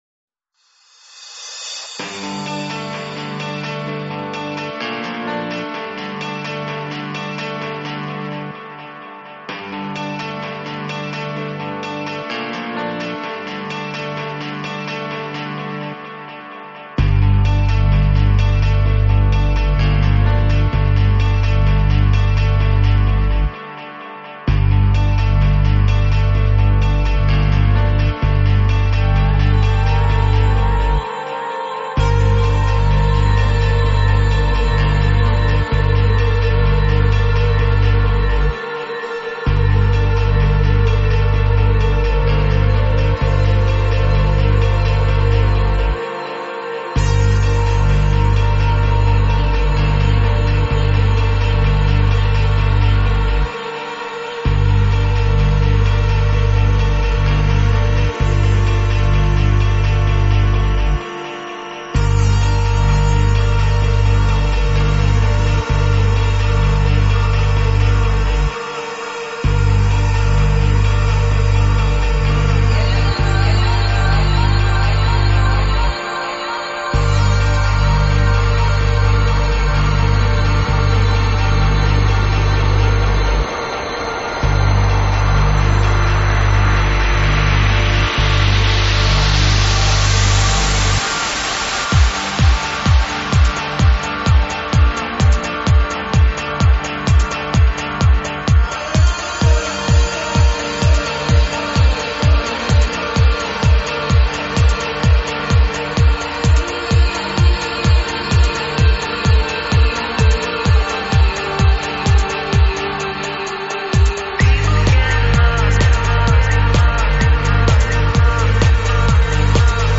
транс сборник